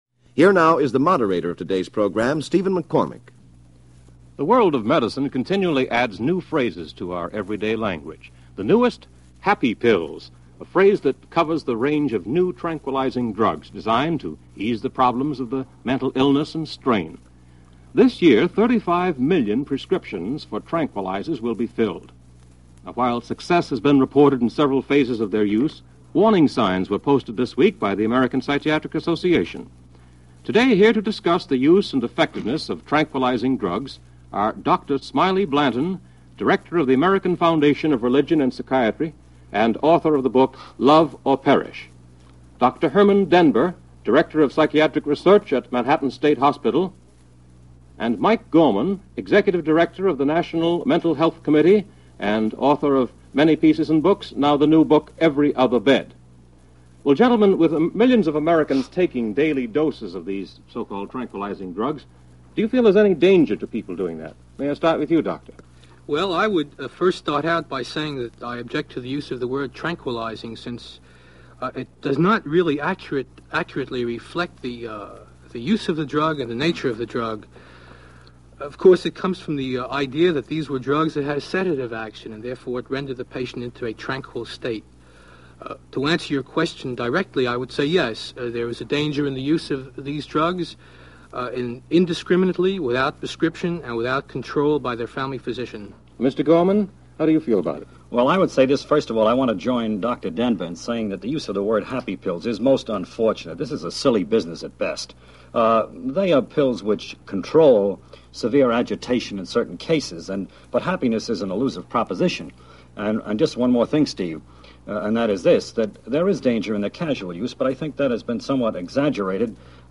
This program, part of the American Forum Of The Air series from July 8, 1956, brought together three mental health workers who had varying opinions and experiences in the field.